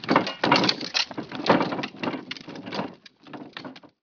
wagon4.wav